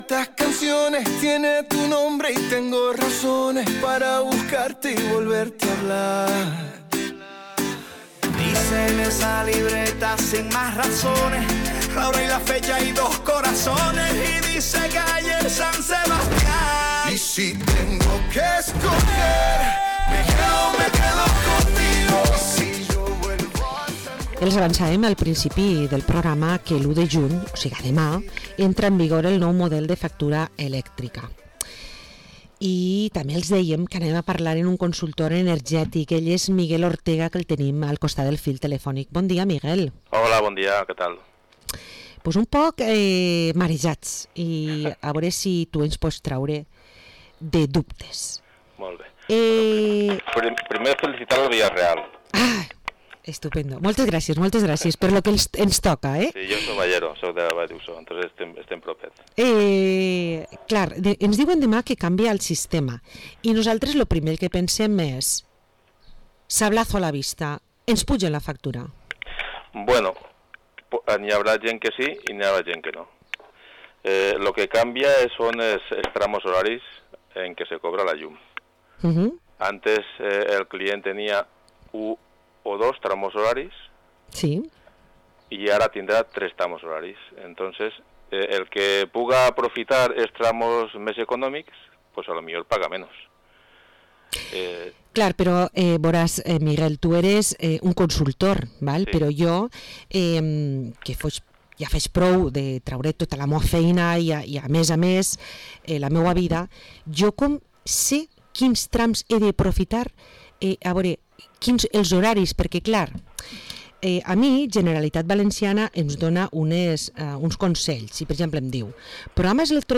Entrevista al consultor energético